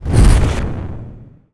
fireball_blast_projectile_spell_01.wav